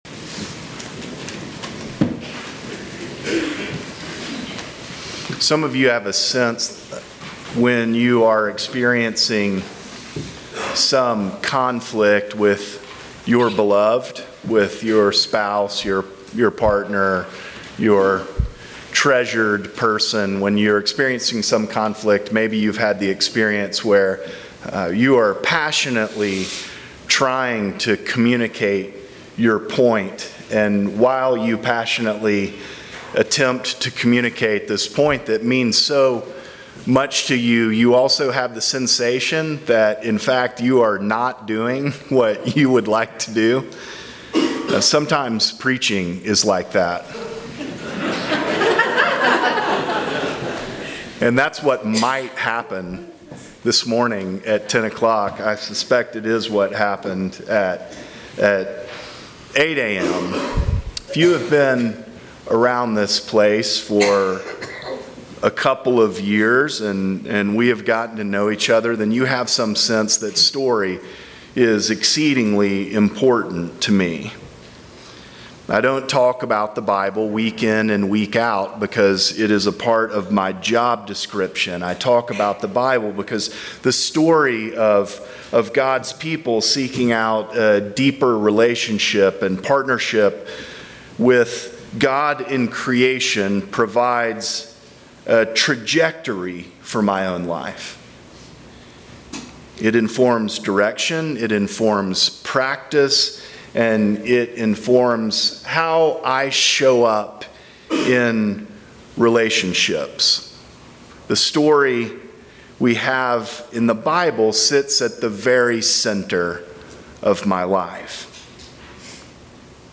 Sermons from St. John's Episcopal Church